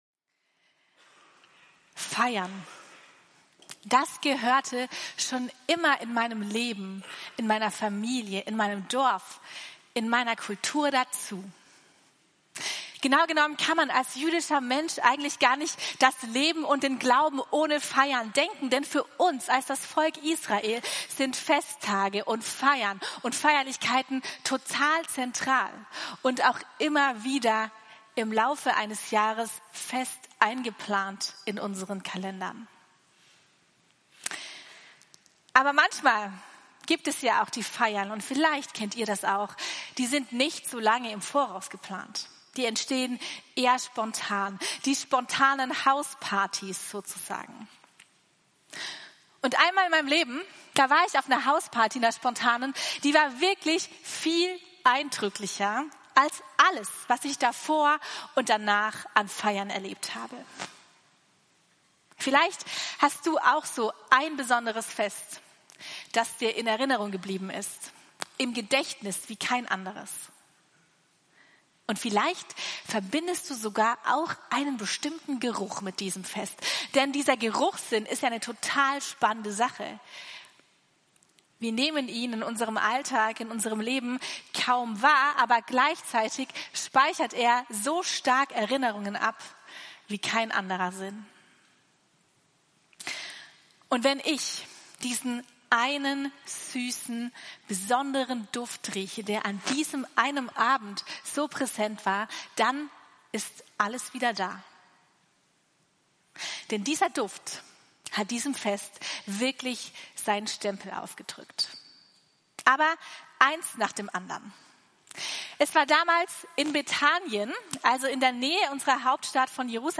Mehr erfahren → Letzte Predigt Jesus begegnen... beim Feiern